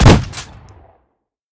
pump-shot-3.ogg